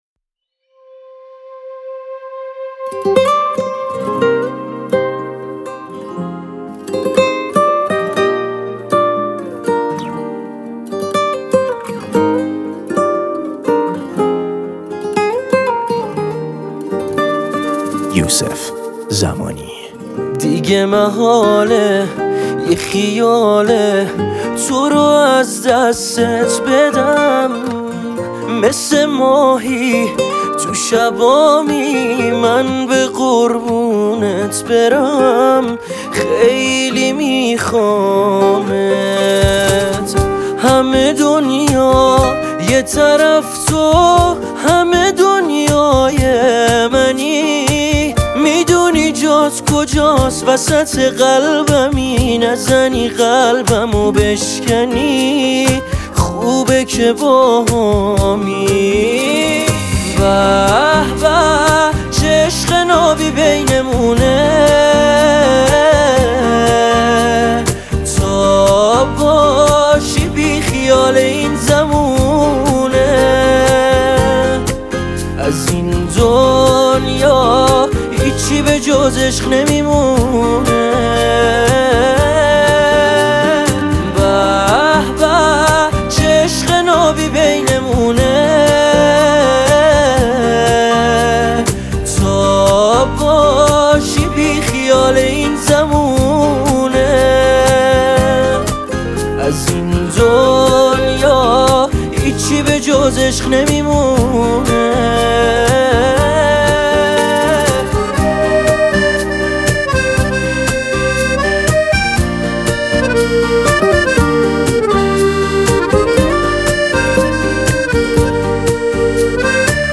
آهنگ شاد آهنگ دلبرانه